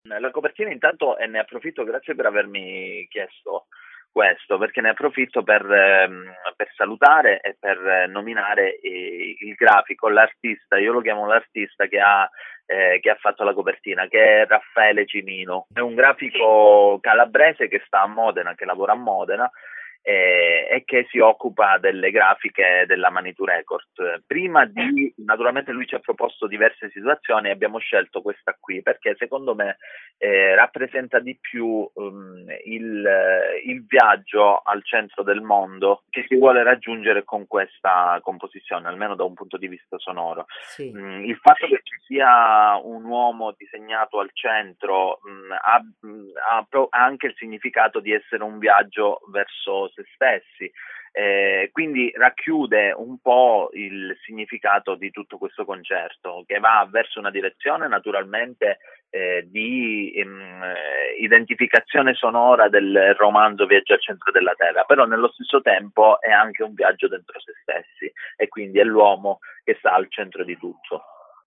La telefonata